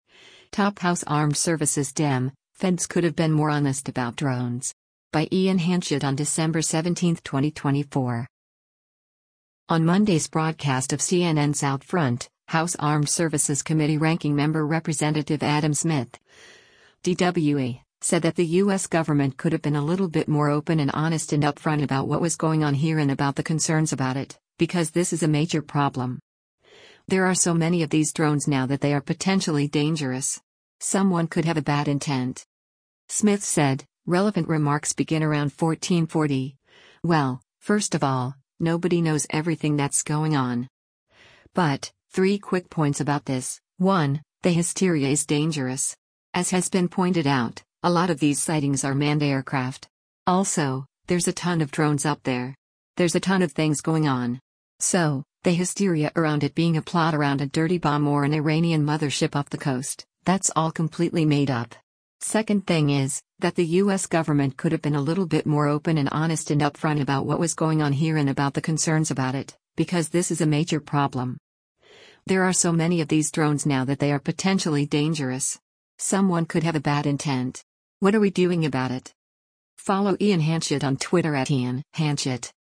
On Monday’s broadcast of CNN’s “OutFront,” House Armed Services Committee Ranking Member Rep. Adam Smith (D-WA) said that “the U.S. government could have been a little bit more open and honest and upfront about what was going on here and about the concerns about it, because this is a major problem.